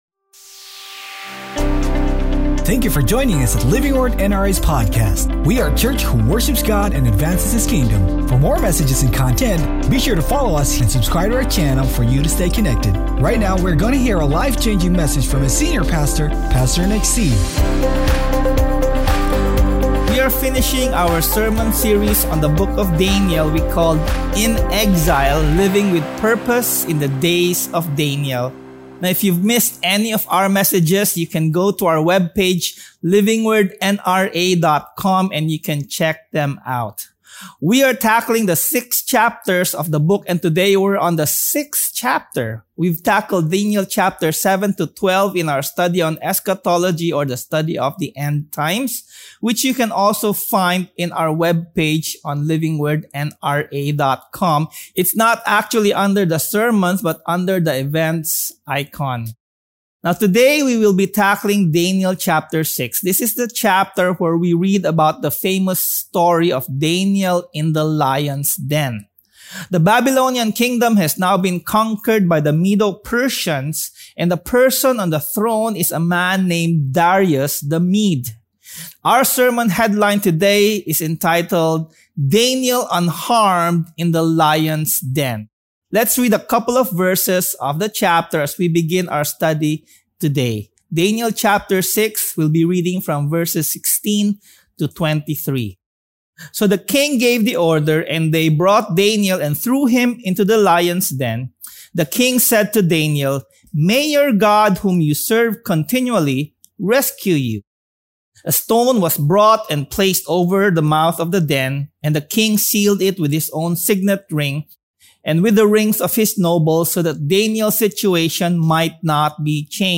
Sermon #6: DANIEL UNHARMED IN THE LIONS’ DEN!
Daniel-Unharmed-AUDIO-Sermon.mp3